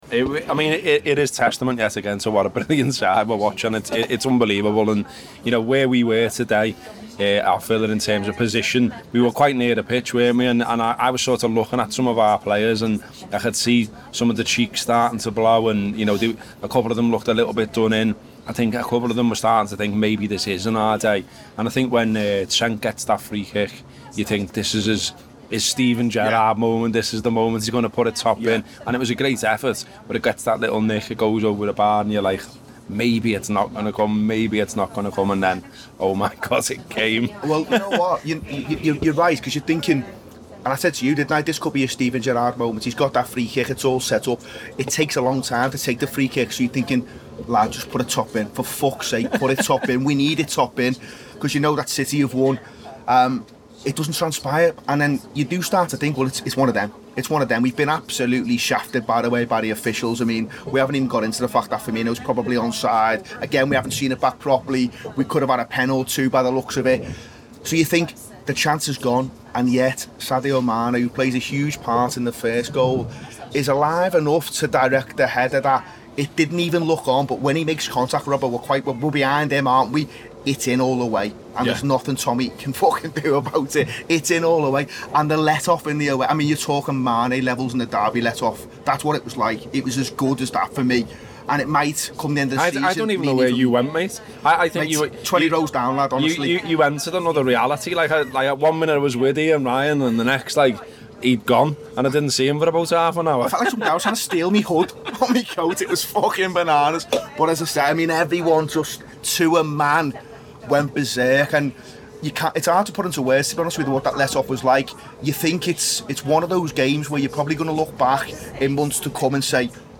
by The Anfield Wrap | Nov 2, 2019 | app, Podcast, Post-Match Show, TAW Player | 0 comments